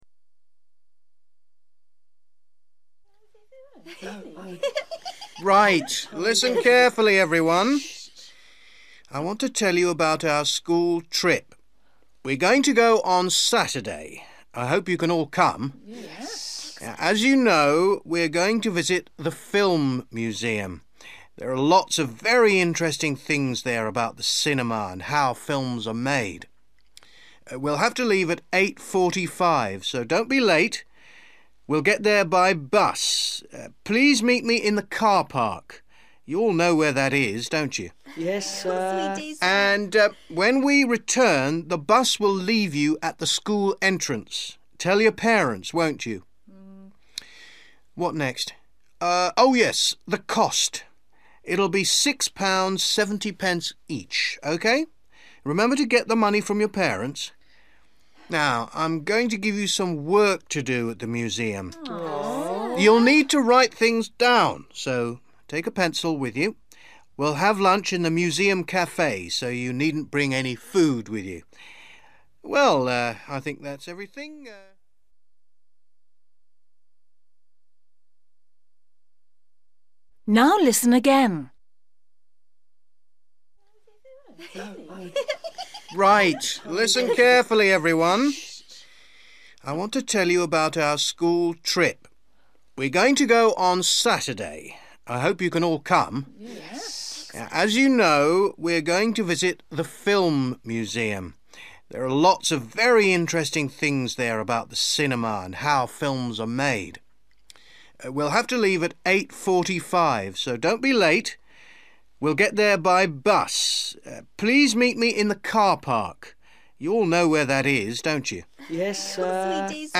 You will hear a teacher talking about a school trip.